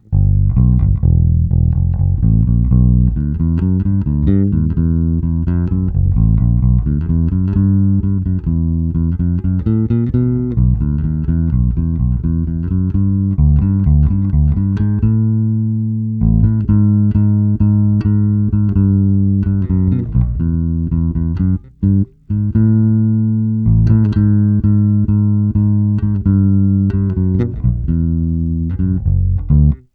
Zvuk: Fender American Deluxe V, oba snímače, korekce rovně, předzesilovač Fender TBP 1, korekce rovně, nahráno linkou (omluvte cvrčka).